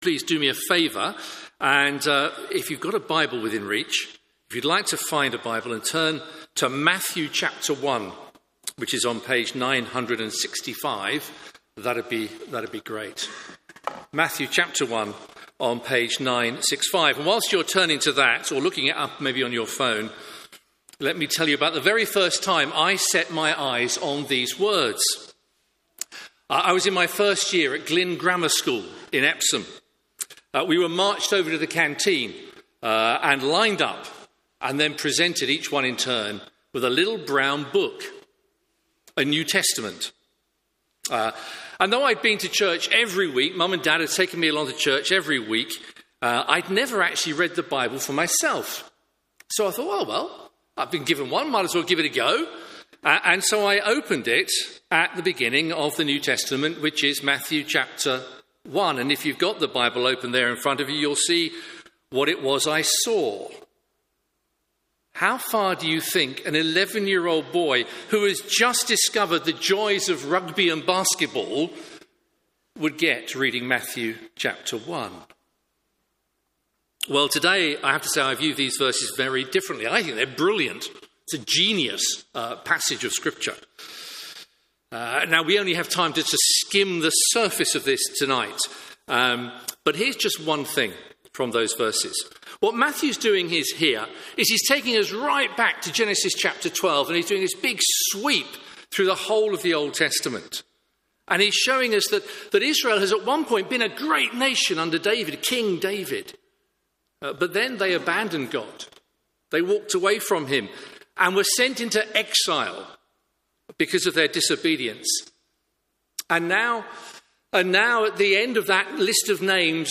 Media for Evening service on Sun 22nd Dec 2024 18:30 Speaker
Theme: Sermon